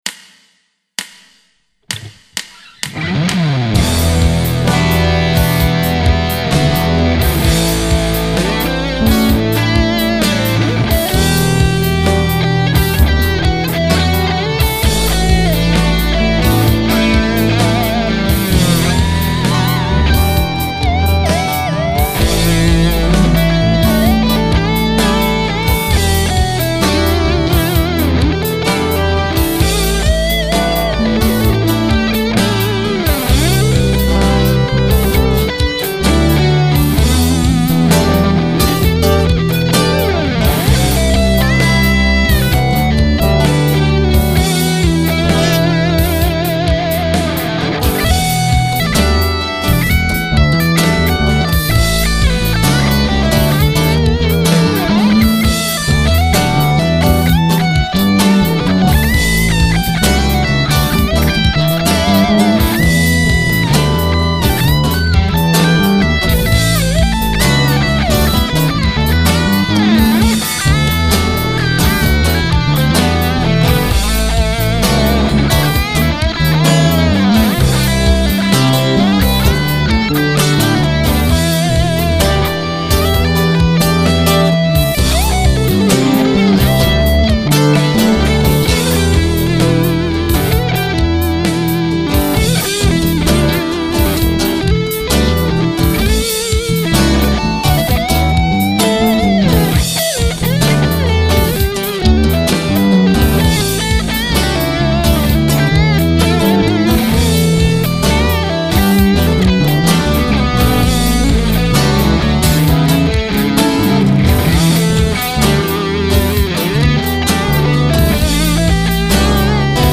Nach den beiden etwas sperrigeren Jams etwas Knusperleichtes für zwischendurch:
Dicke-Hosen-Setup Ibanez RG Bridge+Neck-HB, Axe Mark 4, Echo ist wiederum Cubase. Ich habe versucht, im Mix die Piezo-Akustikgit zu entschärfen.